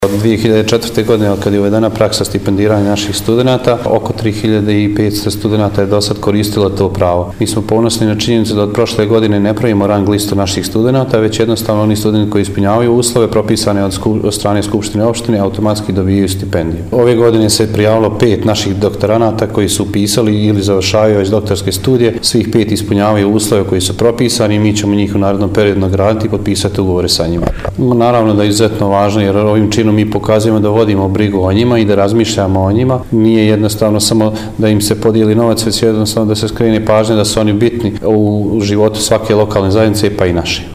izjavu
DRAGAN-VODJEVIC-NACELNIK.mp3